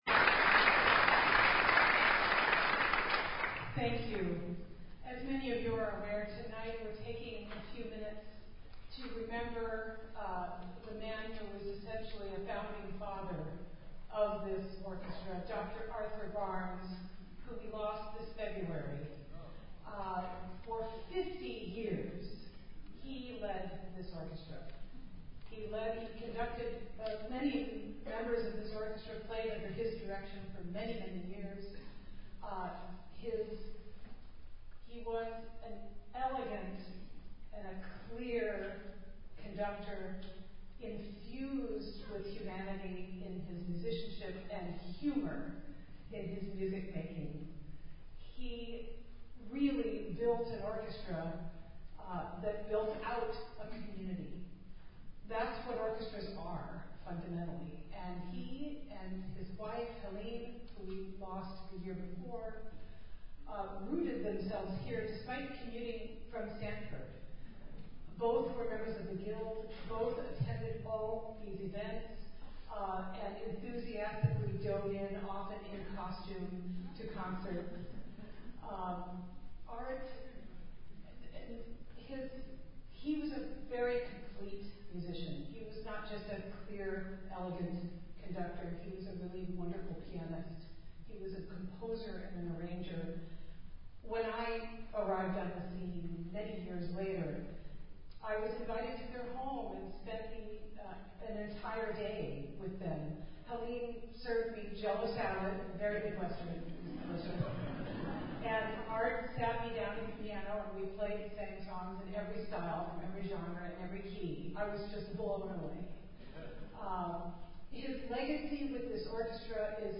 at the June 2024 LAS concert (7-min., 1.8MB mp3).